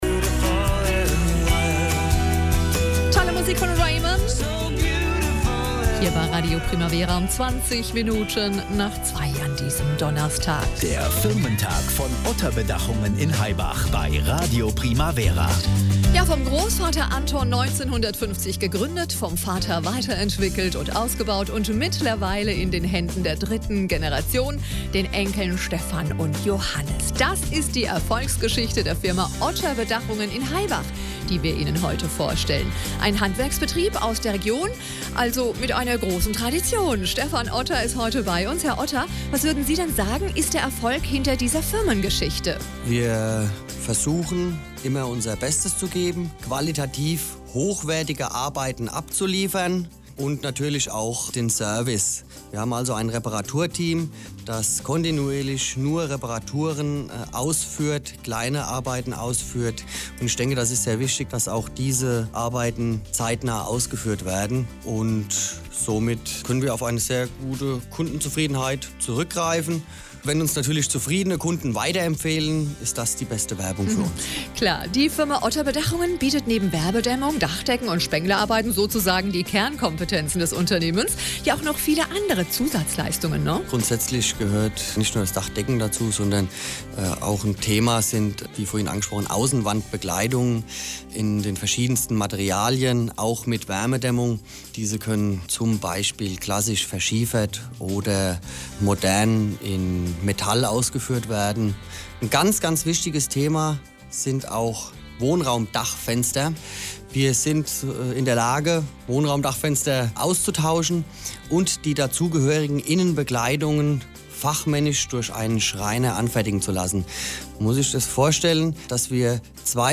Interview beim Firmentag von Radio Primavera